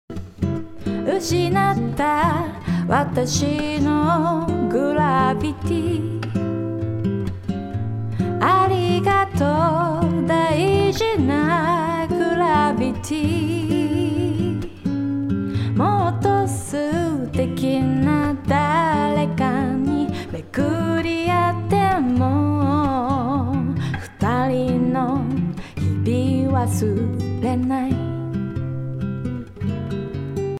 ギターとボーカルだけのシンプルな状態で聴いてみましょう。